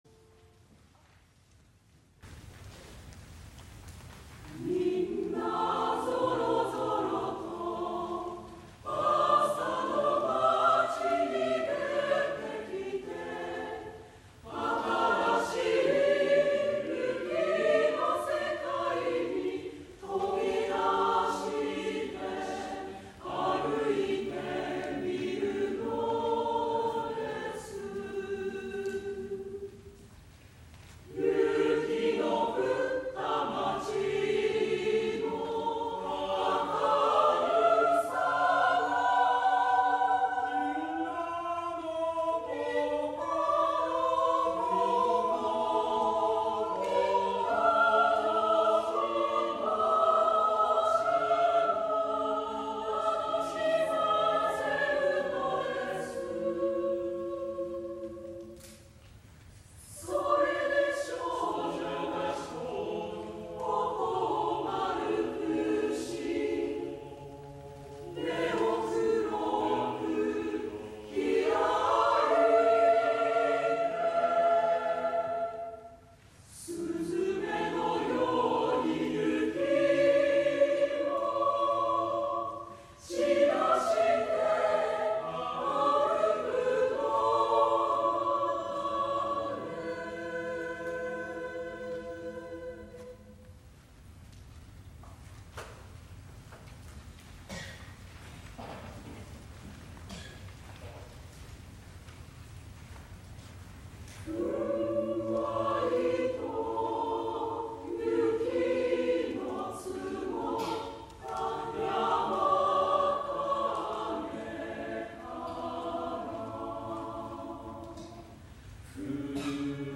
12月8日　14時開演　三条中央公民館
出演　紫苑コーラス　栃尾混声合唱団　道のつどい　主な演奏曲
高田三郎作曲　高野喜久雄作詩　　混声合唱組曲「水のいのち」